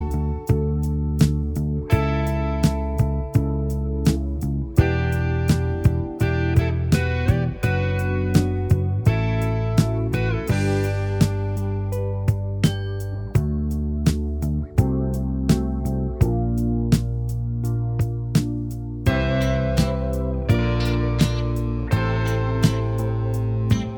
Minus All Guitars Pop (1970s) 4:53 Buy £1.50